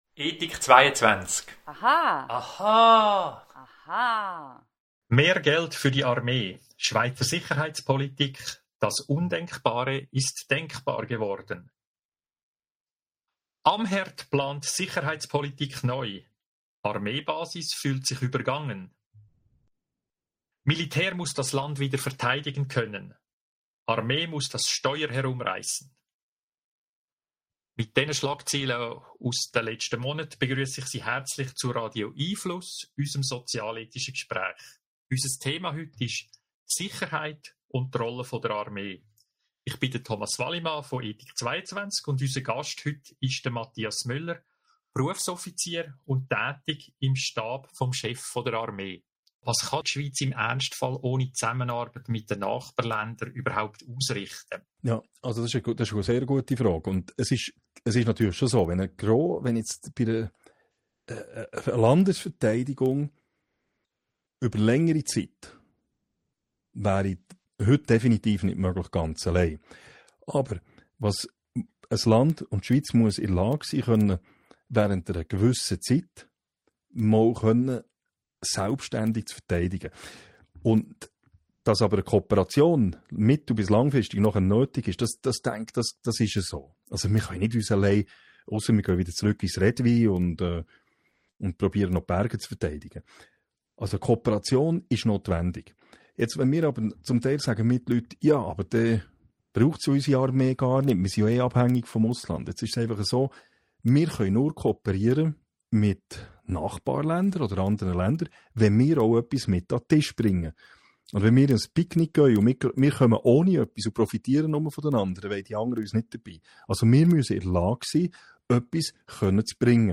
Podcast hier zuhören Radio🎙einFluss findet jeden Mittwochabend von 18:30 - 19 Uhr statt .
Bleiben Sie über die kommenden Radio🎙einFluss Audio-Gespräche informiert!